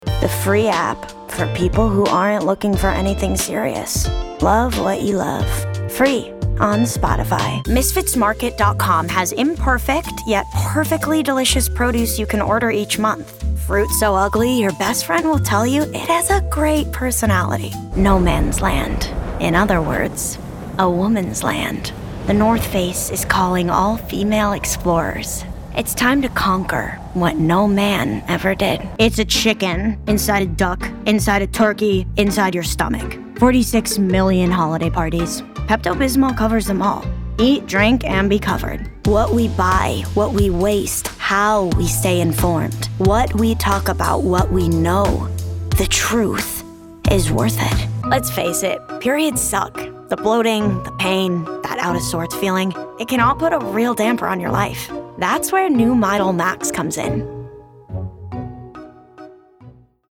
New York : Voiceover : Commercial : Women
Commercial Demo